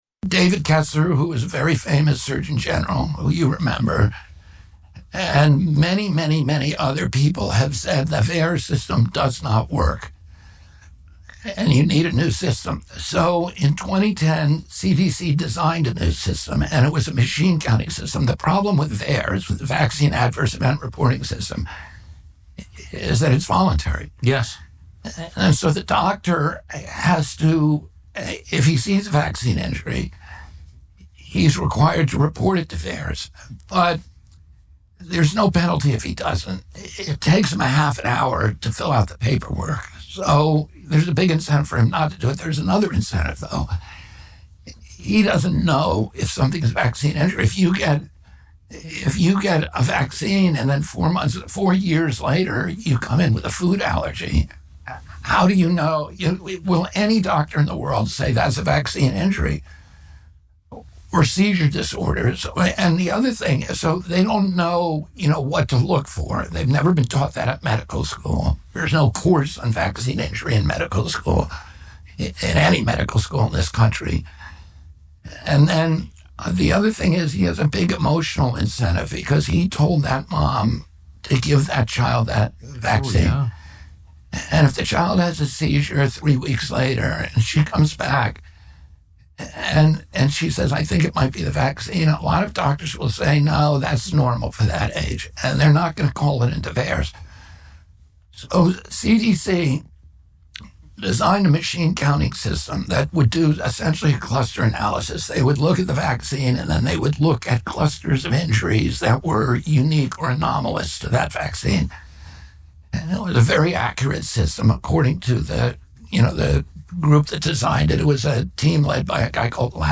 RFK Jr. Interview:
RFK Jr. did an interview with Tucker Carlson earlier this week where the two discussed vaccines.